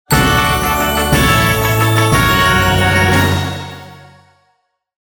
Download Winning sound effect for free.